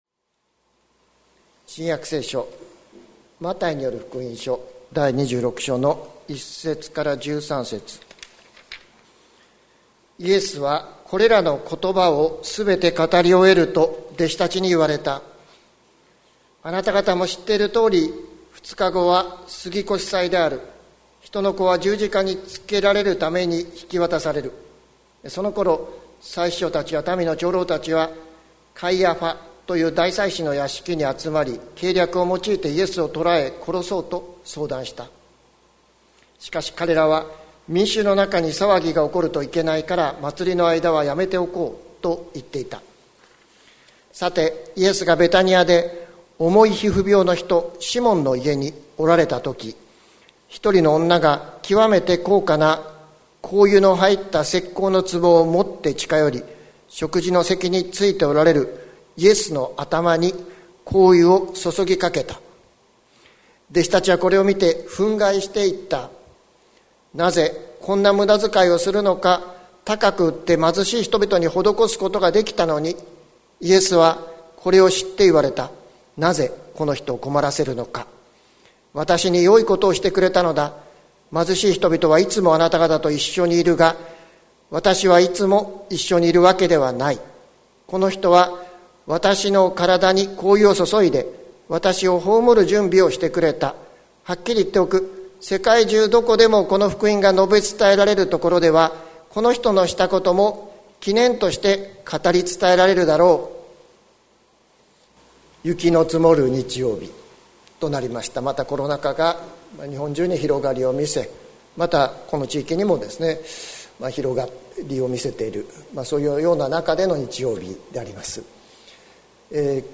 2021年01月10日朝の礼拝「献げよう、あなたの愛」関キリスト教会
説教アーカイブ。